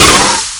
Turbo Dump.wav